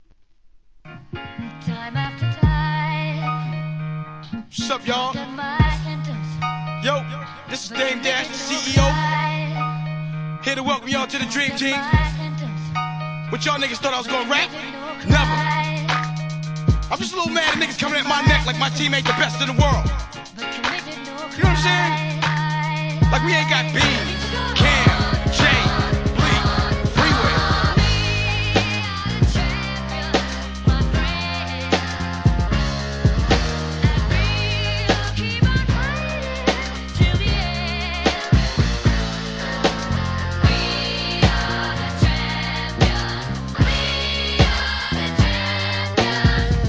HIP HOP/R&B
を下敷きにした半速のビートに